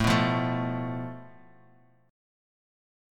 Asus2#5 chord